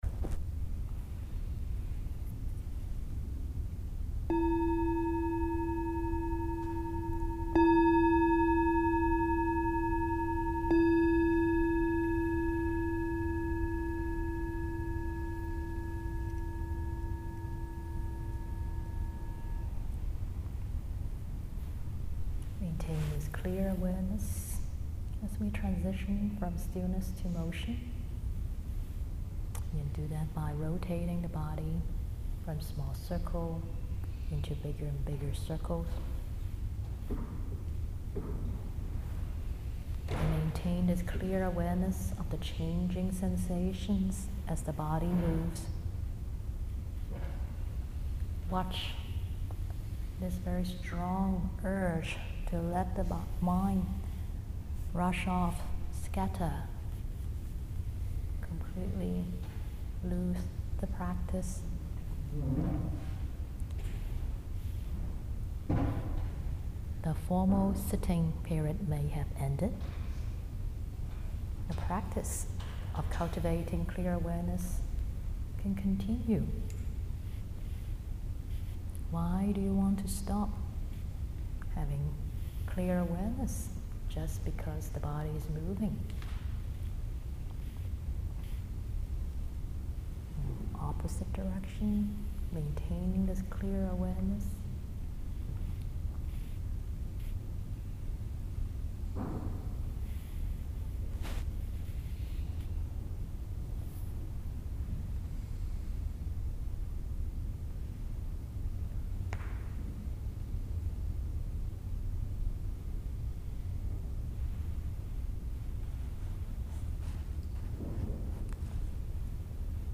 This Dharma talk was given to the Buddhist sangha at Yale University on February 25, 2020.